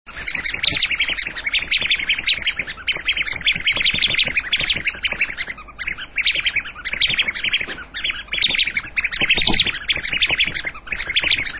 parrots.mp3